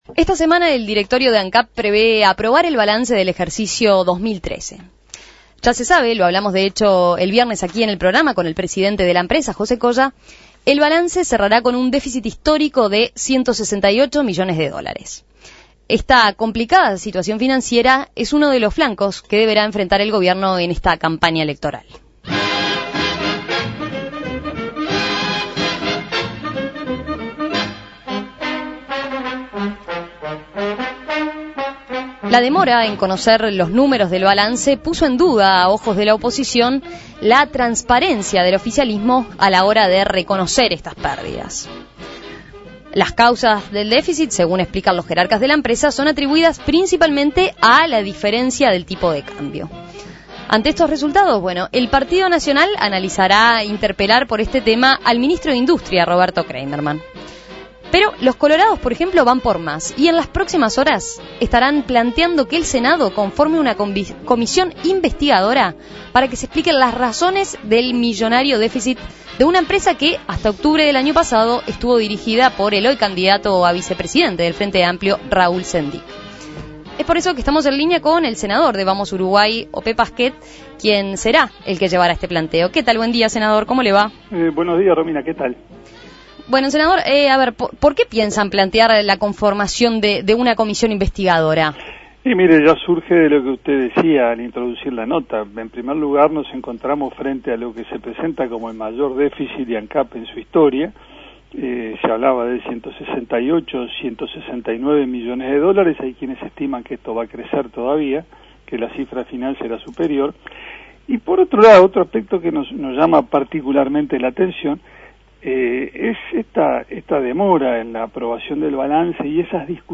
Desde el Partido Colorado quieren conformar una comisión investigadora en el Senado para tratar a fondo el tema de la millonaria deuda de Ancap. Para conocer los fundamentos de la conformación de esta comisión, En Perspectiva entrevistó al senador de Vamos Uruguay Ope Pasquet.